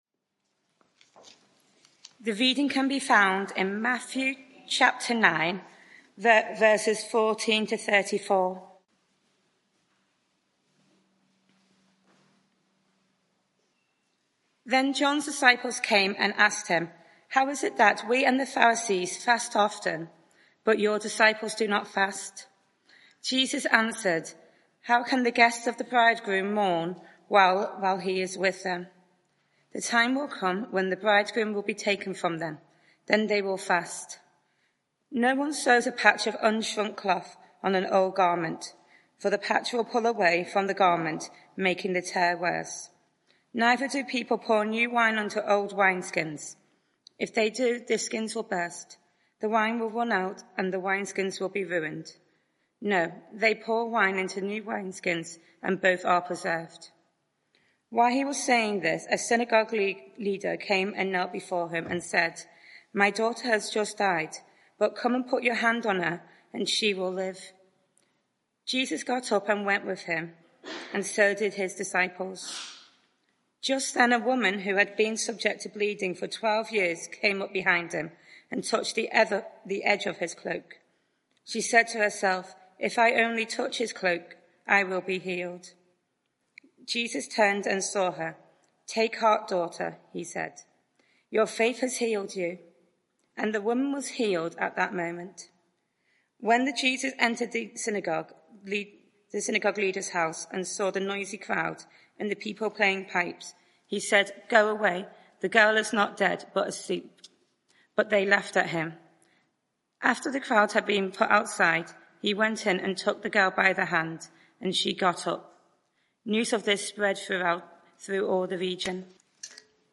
Media for 6:30pm Service on Sun 12th May 2024
Series: Jesus confronts the world Theme: Jesus' trustworthiness Sermon (audio)